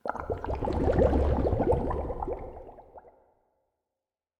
Minecraft Version Minecraft Version 25w18a Latest Release | Latest Snapshot 25w18a / assets / minecraft / sounds / block / bubble_column / whirlpool_ambient1.ogg Compare With Compare With Latest Release | Latest Snapshot
whirlpool_ambient1.ogg